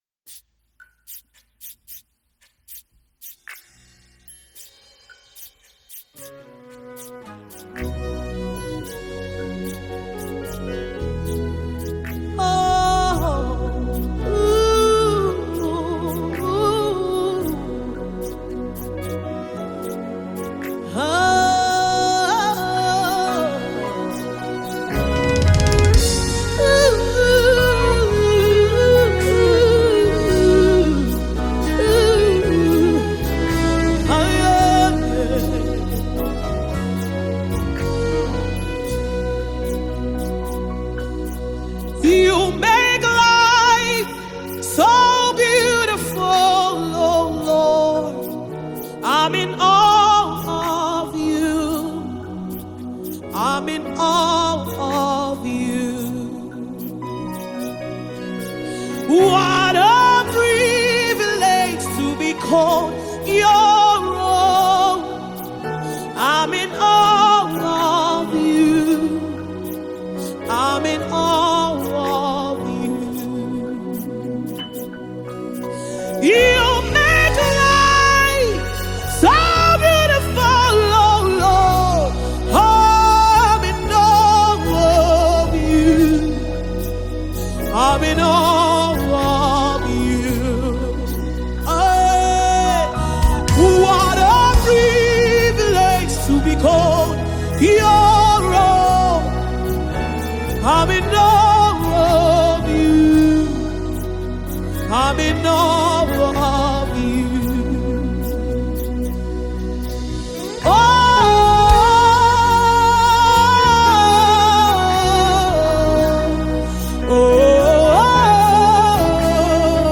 Nigerian Gospel
Genre: Gospel/Christian